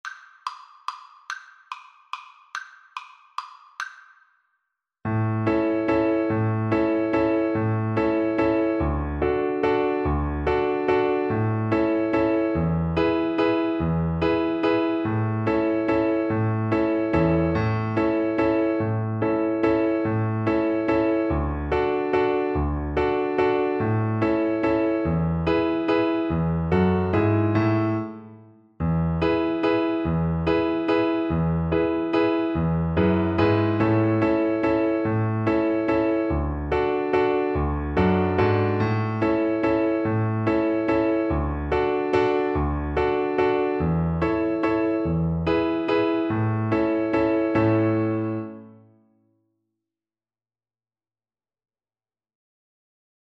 Violin
A major (Sounding Pitch) (View more A major Music for Violin )
3/4 (View more 3/4 Music)
Molto allegro =c.144
Traditional (View more Traditional Violin Music)
Irish